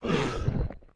Index of /App/sound/monster/misterious_diseased_spear
foot_act_1.wav